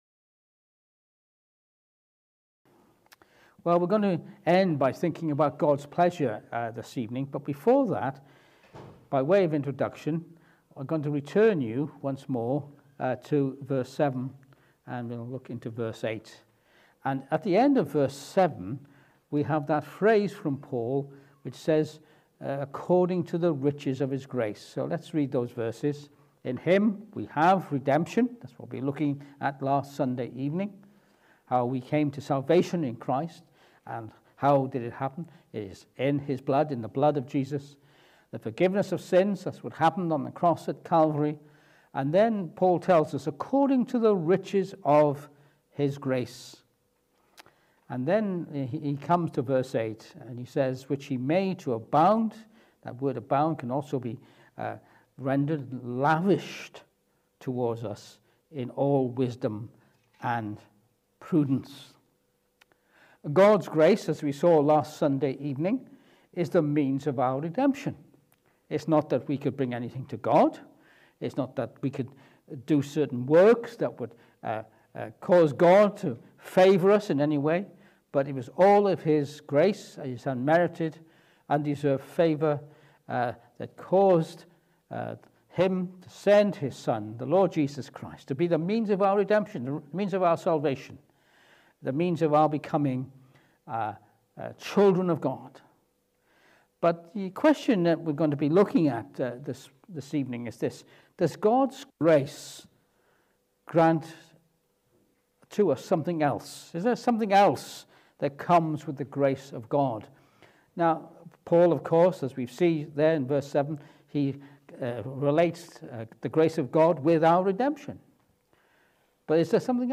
Ephesians 1:8 Service Type: Evening Service This evening we return to Ephesians chapter 1 and verses 8-9.